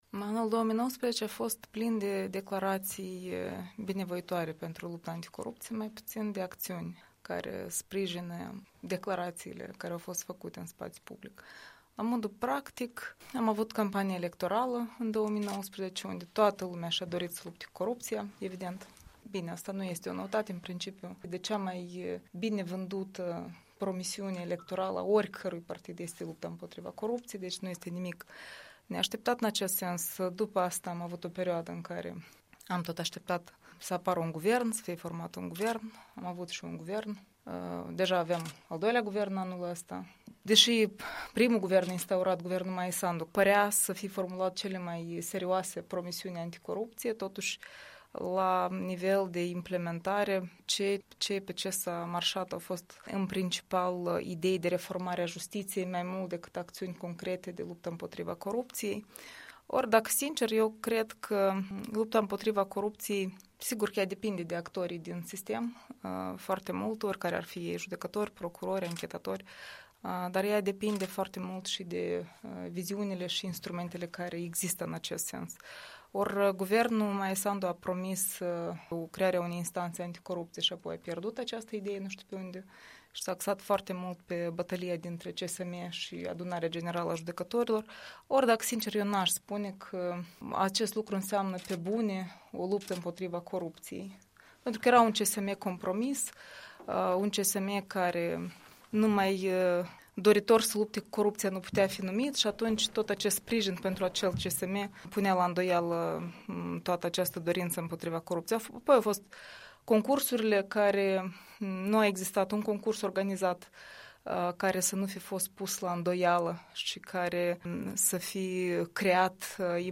Un interviu de bilanț cu fosta directoare adjunctă a Centrului Național Anticorupție din Republica Moldova.